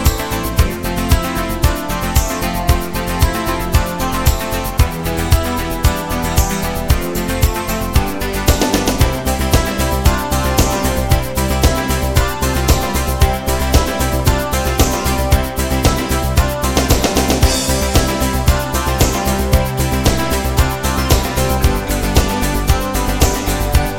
End Cut Down Pop (1980s) 3:20 Buy £1.50